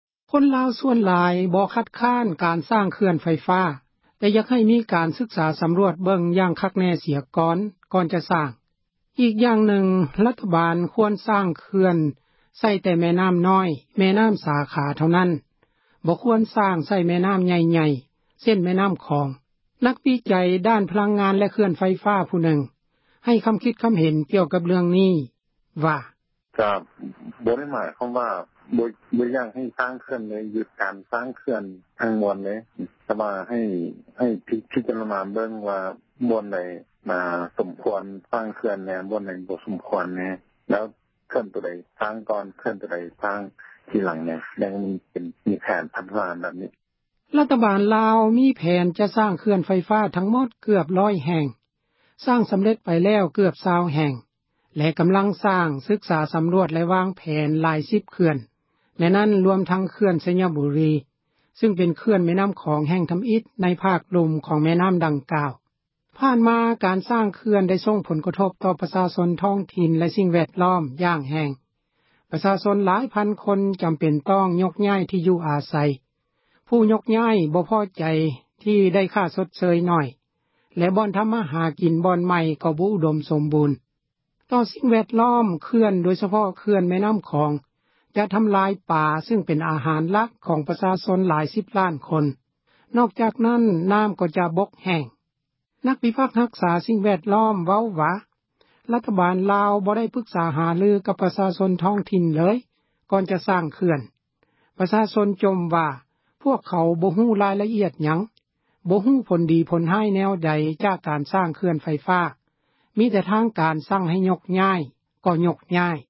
ນັກວິຈັຍ ດ້ານ ພະລັງງານ ແລະ ເຂື່ອນໄຟຟ້າ ຜູ້ນຶ່ງ ໃຫ້ຄຳຄິດເຫັນ ກ່ຽວກັບ ເຣື່ອງນີ້ວ່າ: